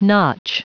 Prononciation du mot notch en anglais (fichier audio)
Prononciation du mot : notch